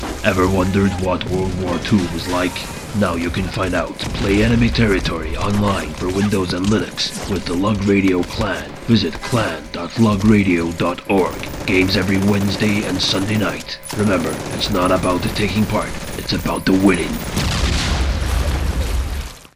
clan.trailer.mp3